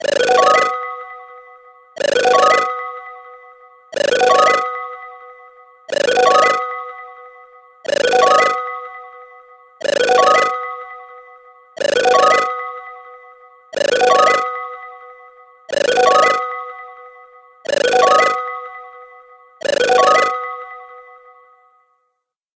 Wake up clock alarm 9 ringtone free download